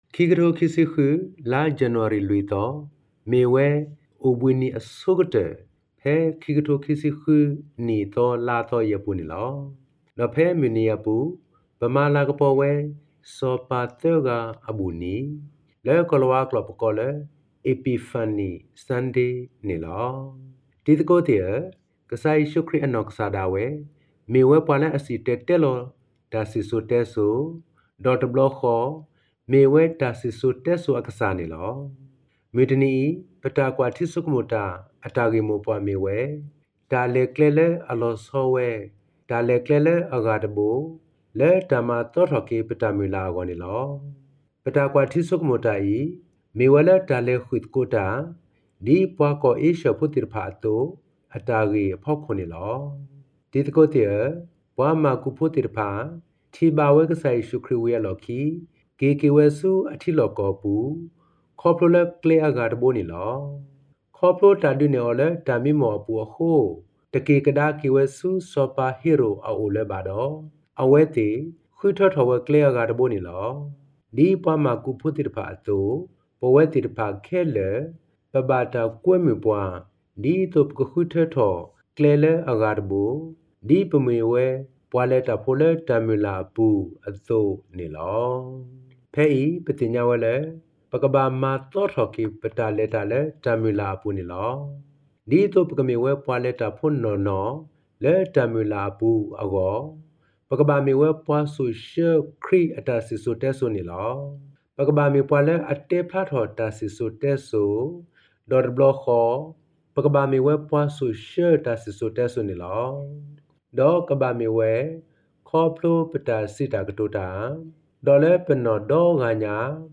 Epiphany-Reflection-in-Karen-Jan-4.mp3